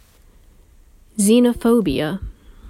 発音に注意してください。